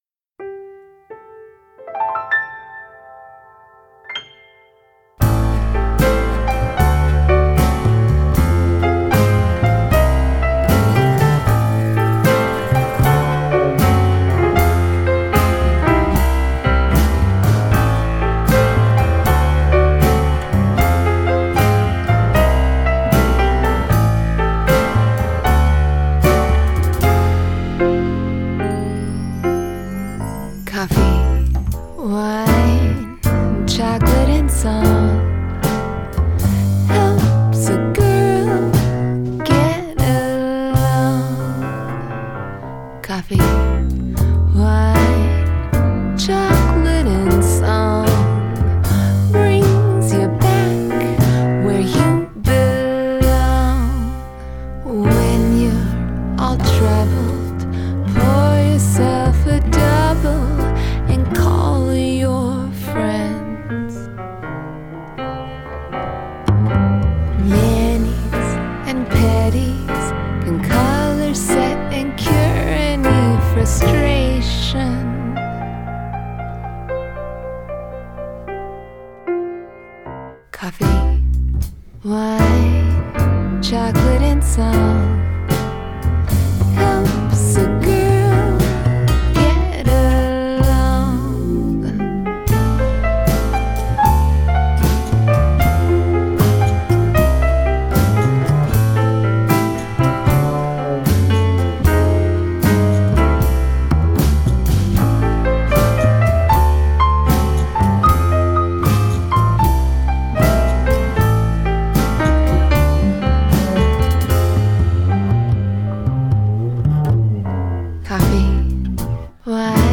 Adult Contemporary
Indie Pop , Musical Theatre